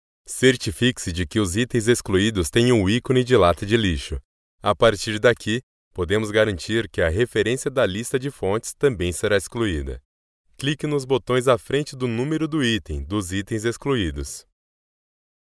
locutor brasil, brazilian voice over